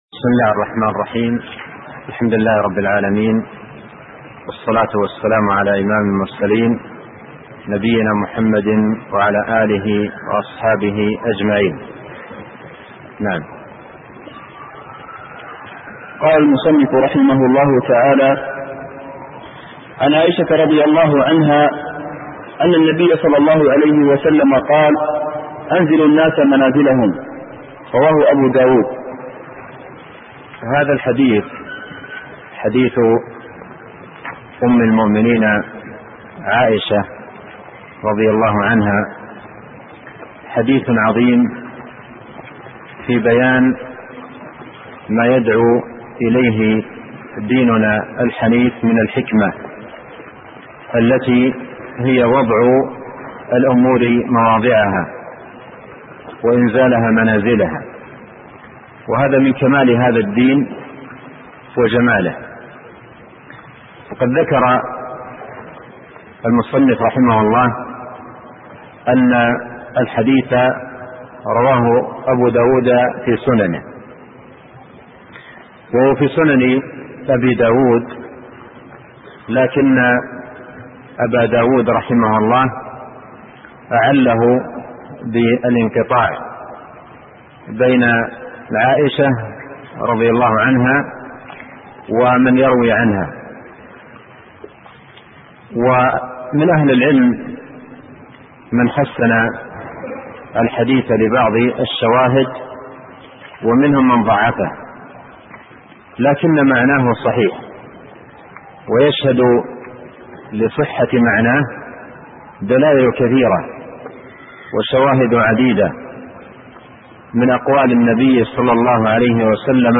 شرح جوامع الأخبار الدرس السابع